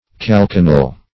Search Result for " calcaneal" : Wordnet 3.0 ADJECTIVE (1) 1. relating to the heel bone or heel ; The Collaborative International Dictionary of English v.0.48: Calcaneal \Cal*ca"ne*al\, a. (Anal.)